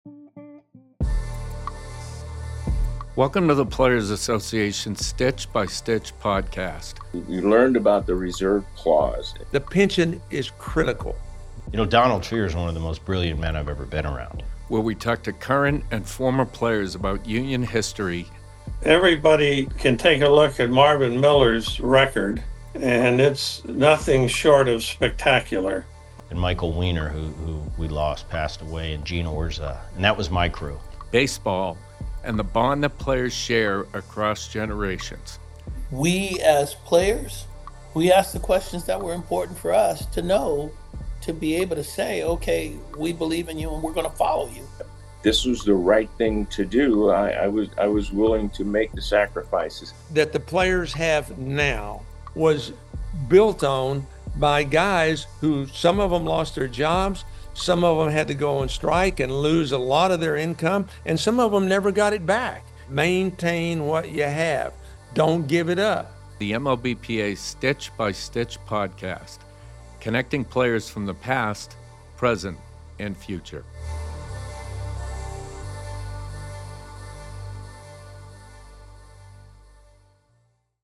For this project we've interviewed Players whose careers cover a span of seven decades, and collected an oral history that charts the union's path and the bonds that unite Players across generations.